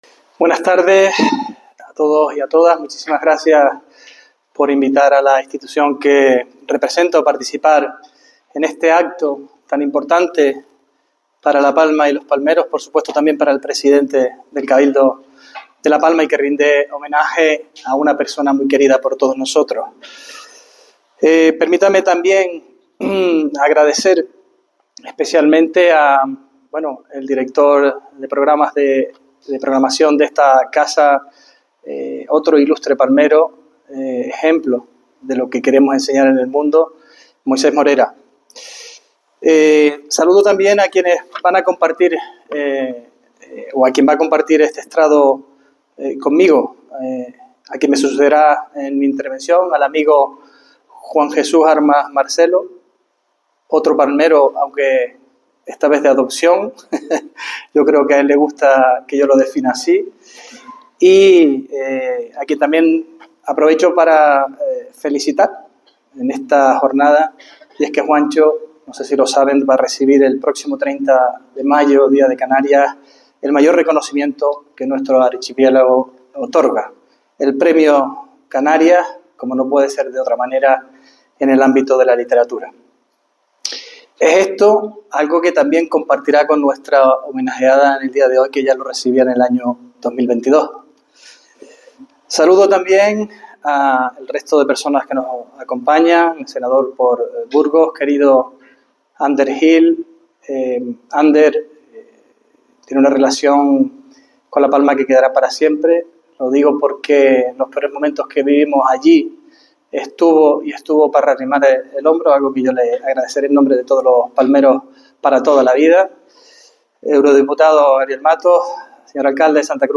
La Casa América, en Madrid, acogió un homenaje a la escritora palmera en el que participaron otras autoras latinoamericanas
El presidente del Cabildo de La Palma, Sergio Rodríguez, participó en el homenaje que la Casa América de Madrid celebró en honor a la escritora palmera Elsa López, en el que puso en valor la aportación literaria y a conformar la sociedad de la Isla, “representando a una generación que sigue apostando por el futuro de la Isla”
Intervención Sergio Rodríguez Elsa López audio.mp3